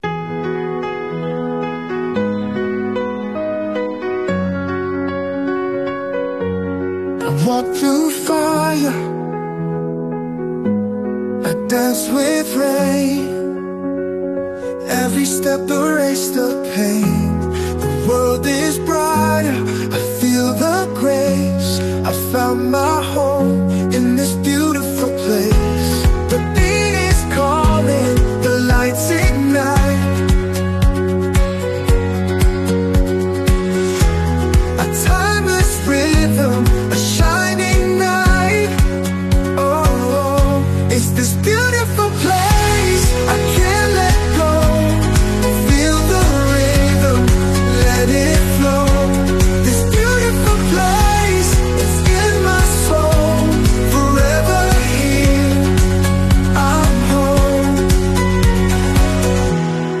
Downtown Vernon BC Canada Sound Effects Free Download